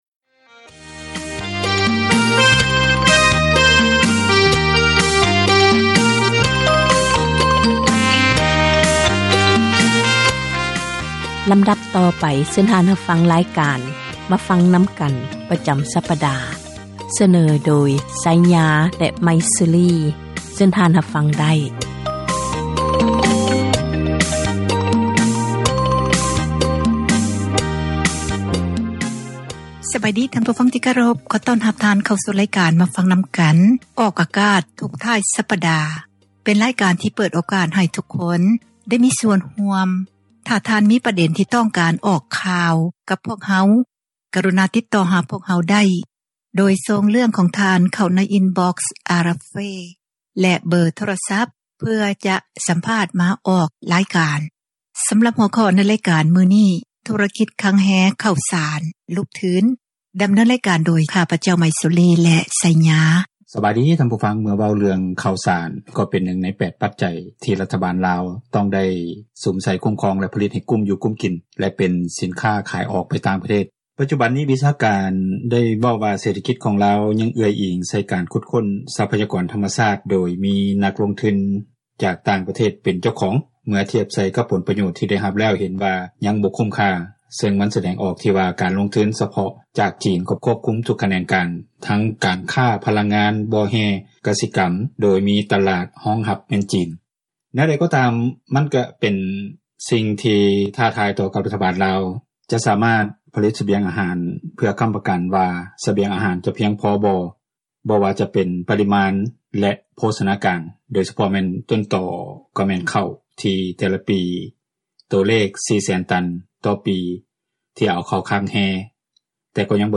ການສົນທະນາ ໃນບັນຫາ ແລະ ຜົລກະທົບຕ່າງໆ ທີ່ເກີດຂຶ້ນ ຢູ່ປະເທດລາວ.